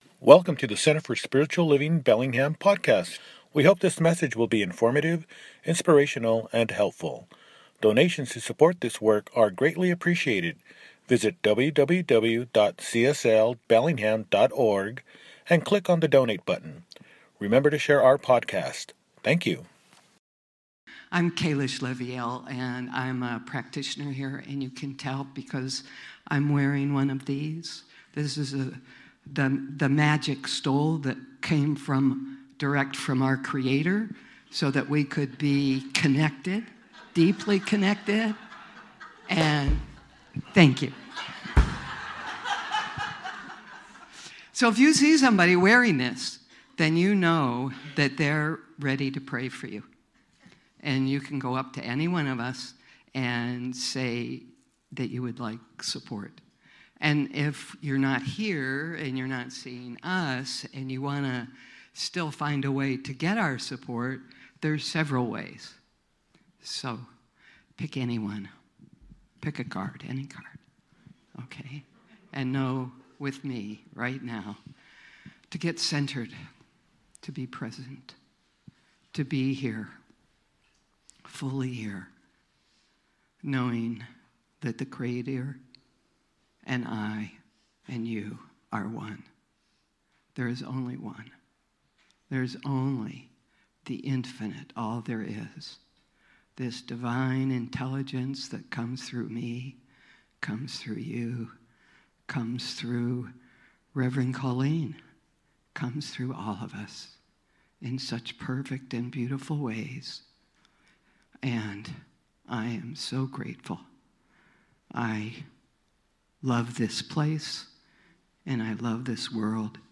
Love Wins! – Celebration Service